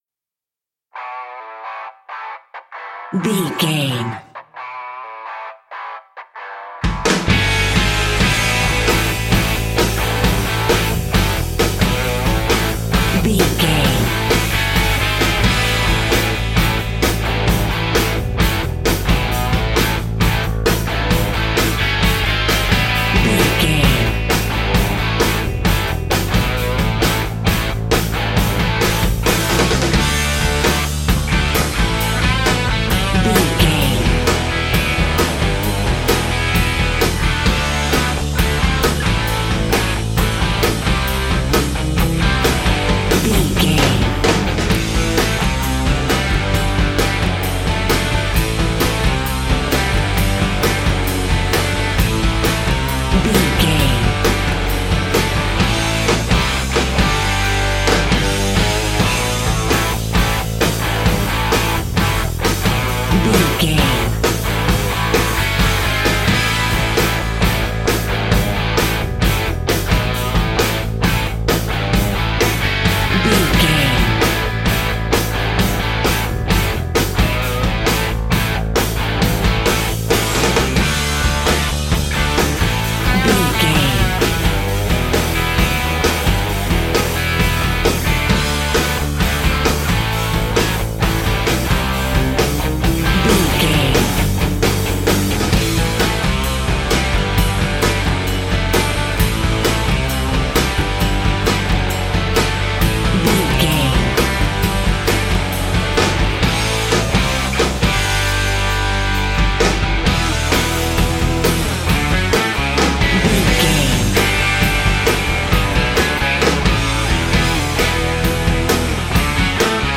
Ionian/Major
drums
electric guitar
bass guitar
hard rock
aggressive
energetic
intense
nu metal
alternative metal